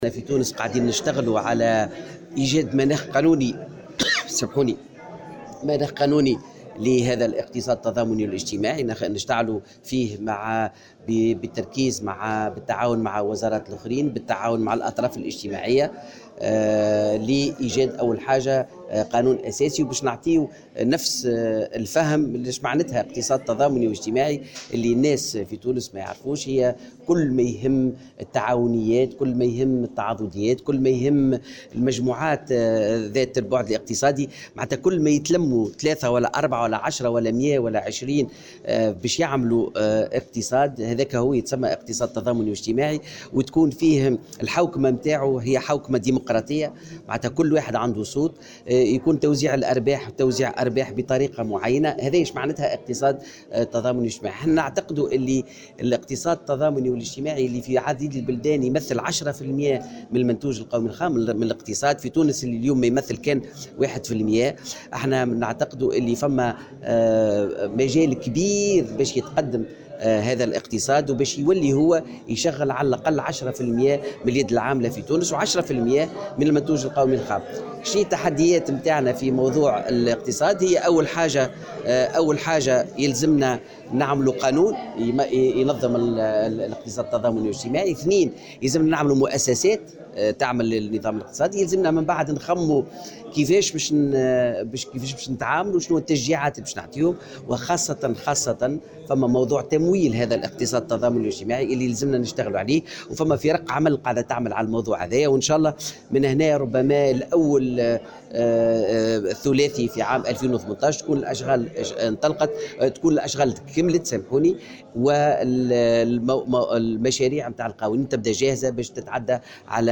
أكد وزير التكوين المهني والتشغيل، فوزي عبد الرحمان على هامش افتتاح المؤتمر الدولي للاقتصاد الاجتماعي والتضامني أن العمل متواصل لإيجاد مناخ قانوني لهذا الاقتصاد بالتعاون مع مختلف الوزارات الأخرى و الأطراف الاجتماعية.